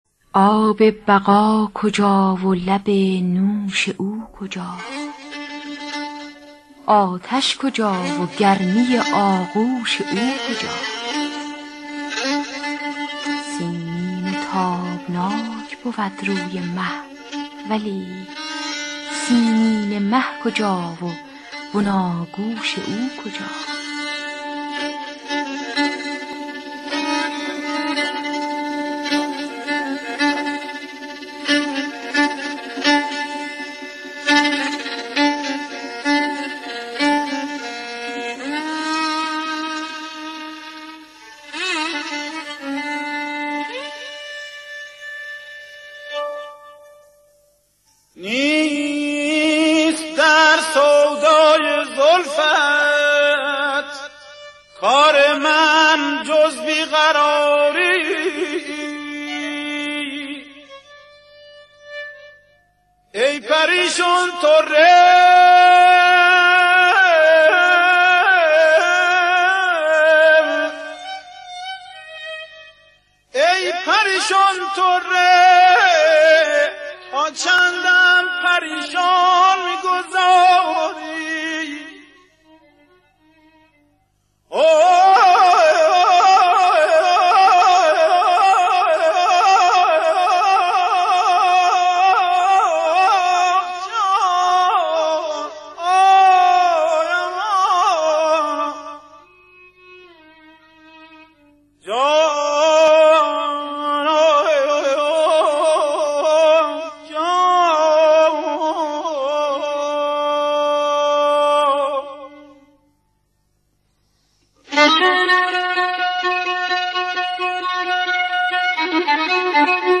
خوانندهٔ موسیقی اصیل ایرانی است .